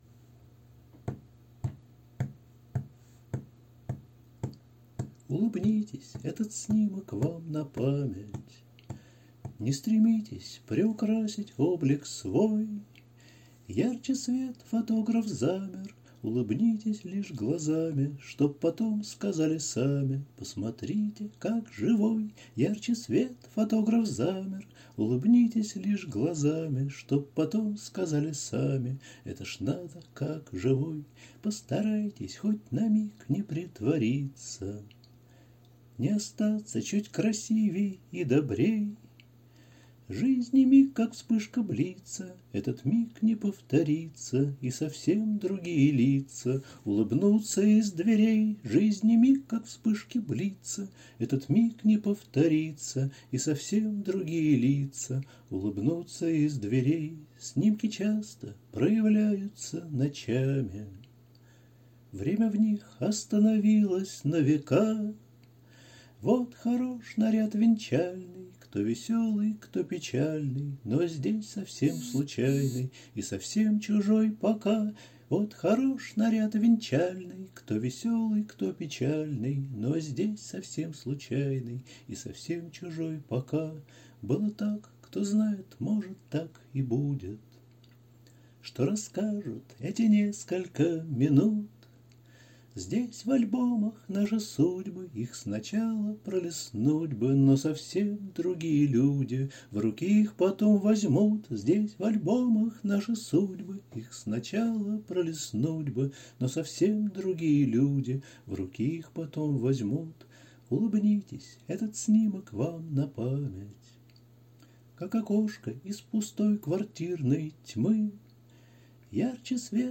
аудиозапись, на которой эта песня спета без аккомпанемента